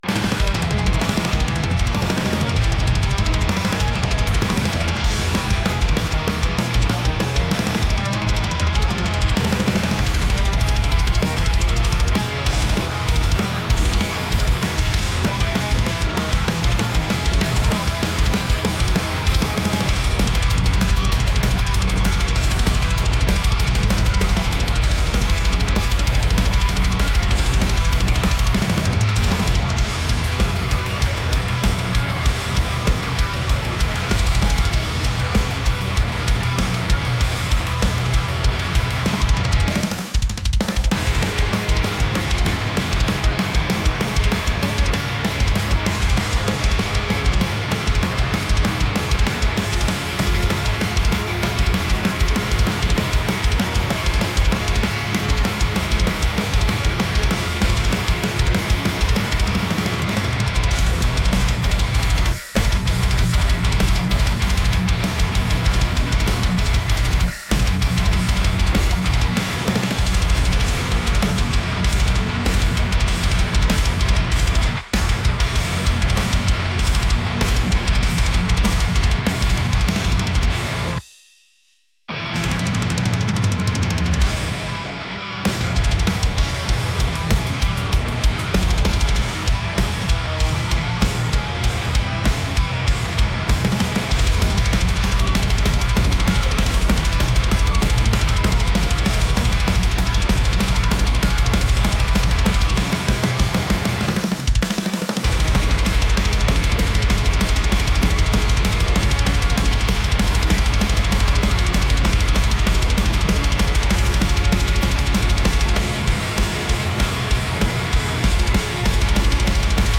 aggressive | intense | metal